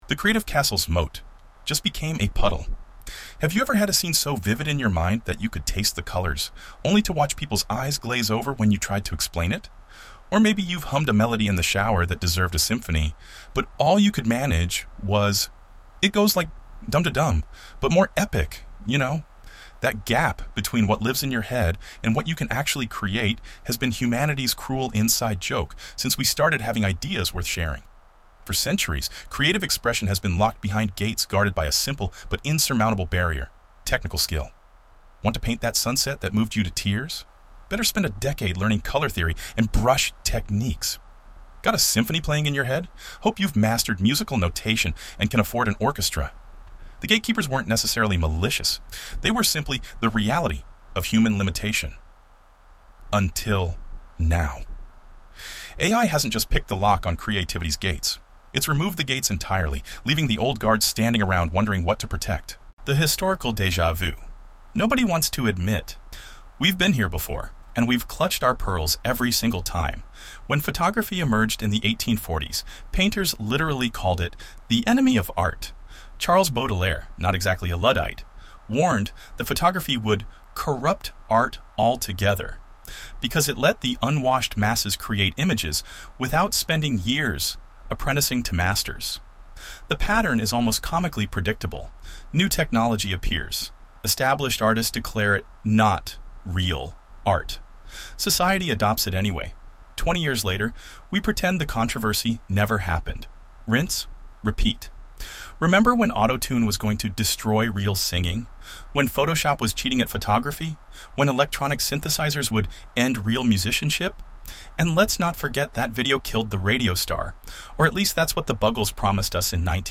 This is an Eleven Labs recording of the article.  8:12 running time.